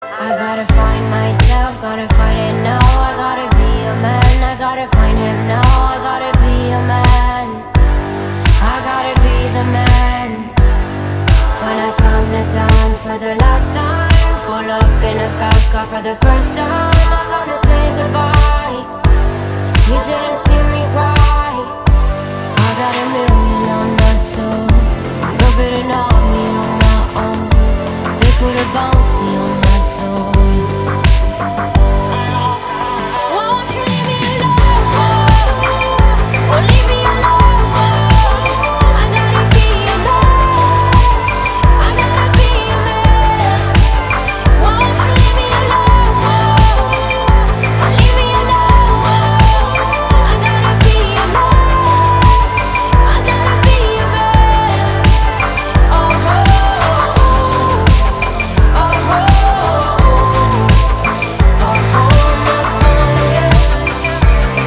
качающие